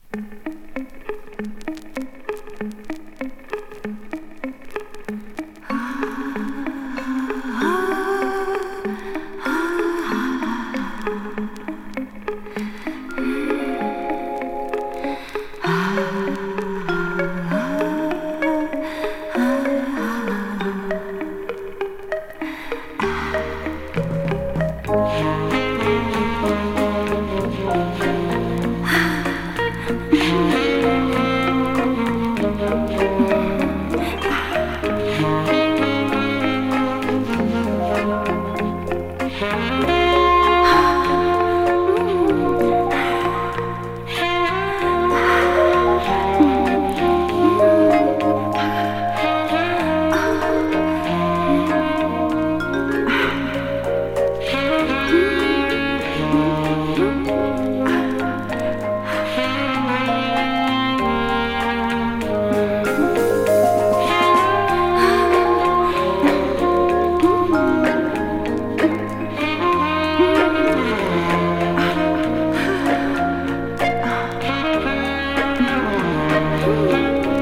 甘ーいイージーリスニングに、恍惚のためいきが重なるセクシーチューンタップリ。ヴィブラフォンの音色も相まって